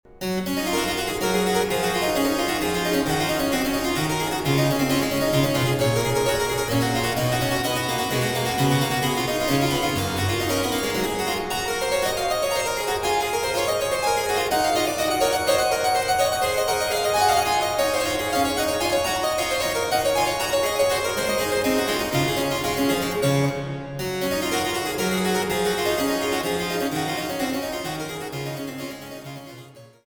Meisterwerke der französischen Gambenmusik